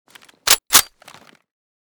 p90_unjam.ogg